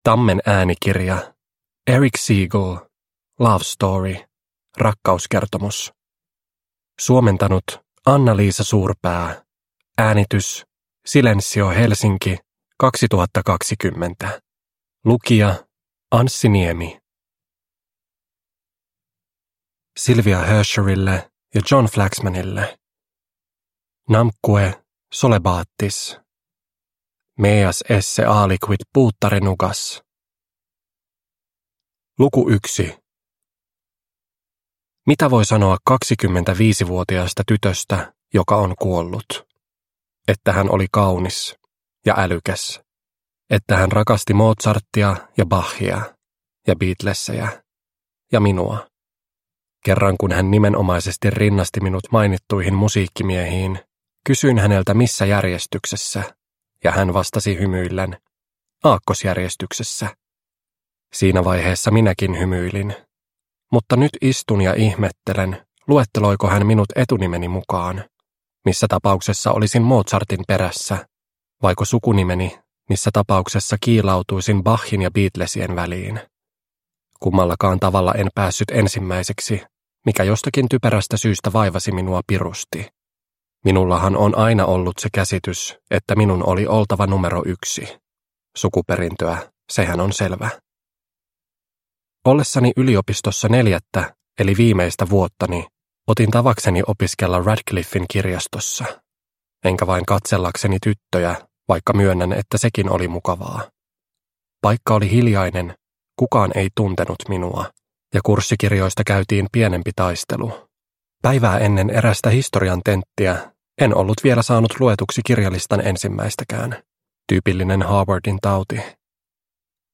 Love Story – Ljudbok – Laddas ner